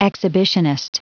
Prononciation du mot exhibitionist en anglais (fichier audio)
Prononciation du mot : exhibitionist